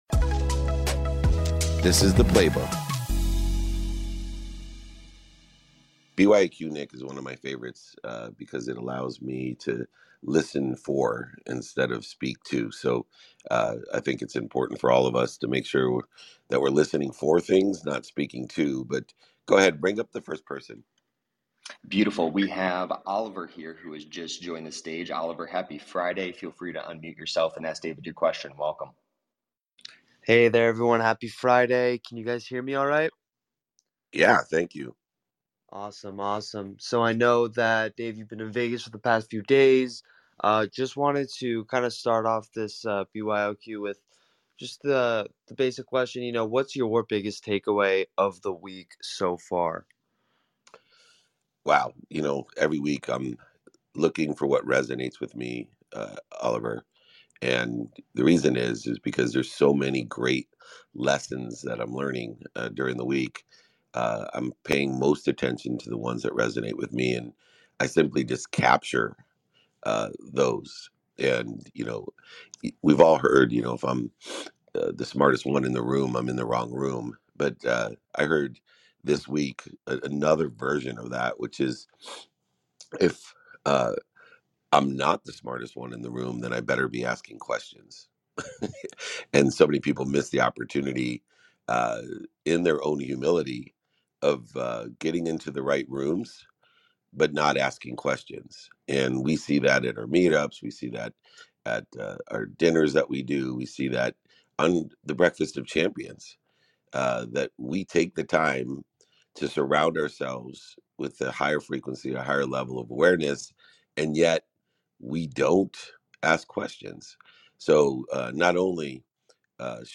In today’s episode, I host a Q&A session that stresses the importance of resonating lessons, aligning team goals, and the power of empathy in business and life. I elaborate on the virtues of consistency, the balance between absorbing environments and asking pivotal questions, and how our inherent value is deeply intertwined with how we use our time.